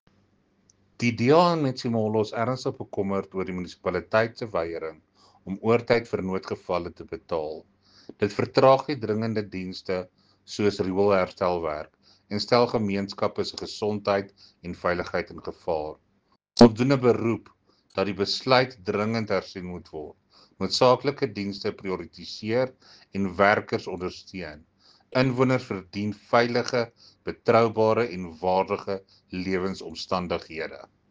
Afrikaans soundbites by Cllr Jacques Barnard and